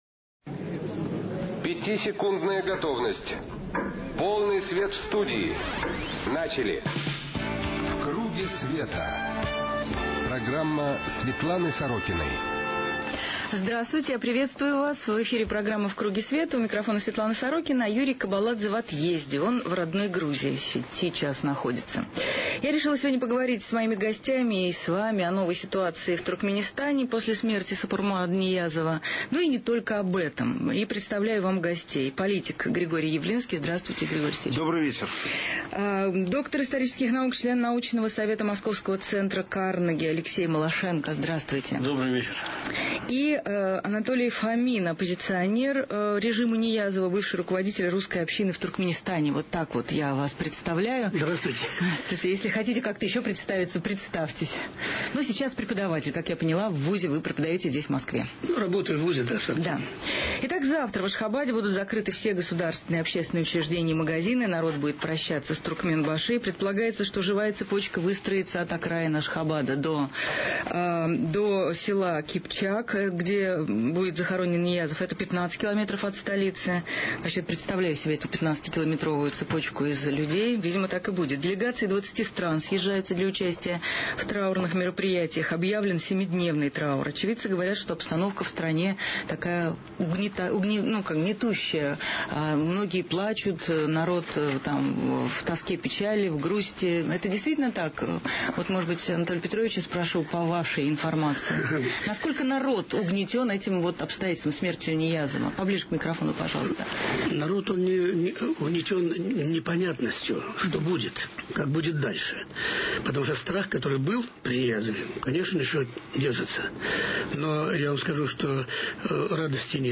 Светлана Сорокина: передачи, интервью, публикации